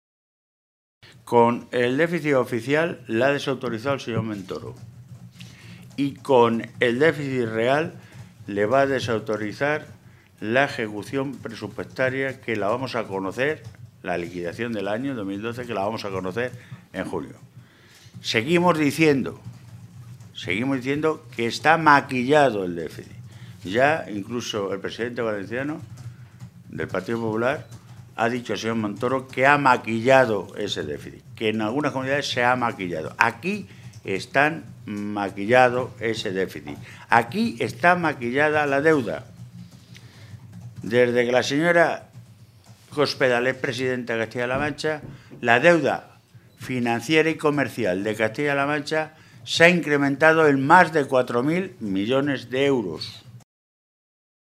Jesús Fernández Vaquero, Secretario de Organización del PSOE de Castilla-La Mancha
Cortes de audio de la rueda de prensa